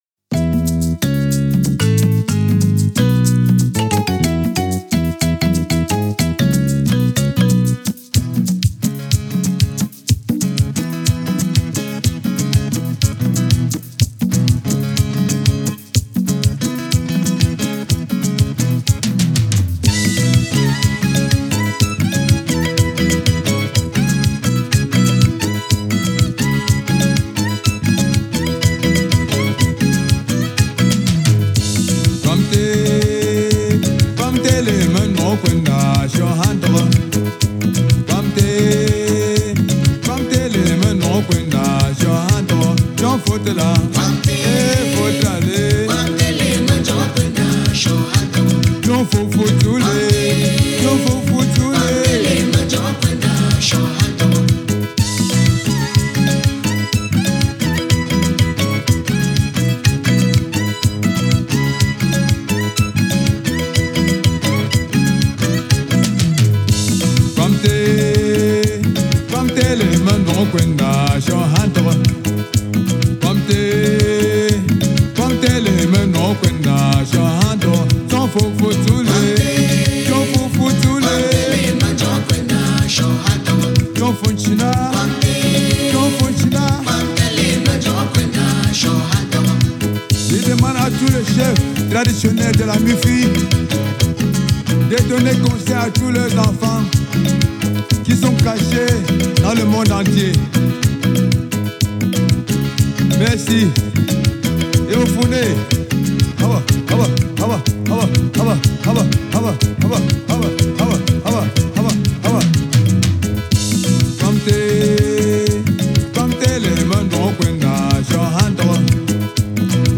Genre: Africa, World